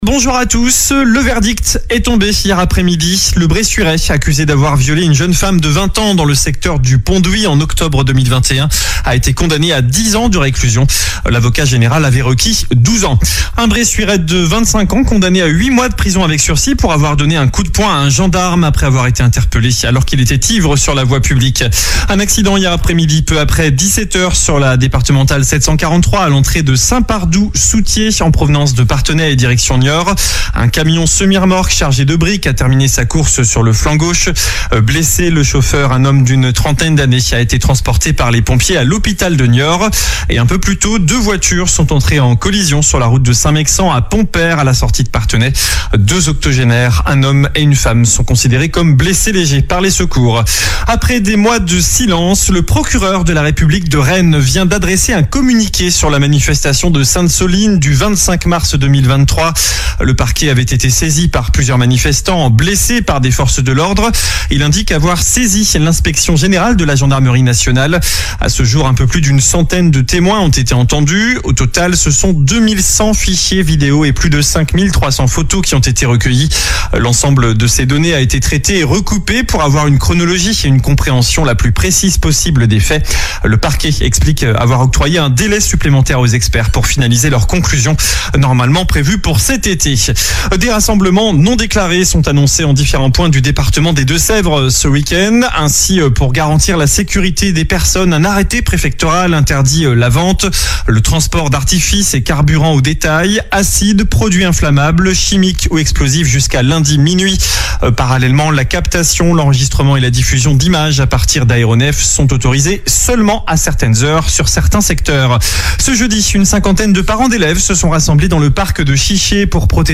Journal du samedi 23 mars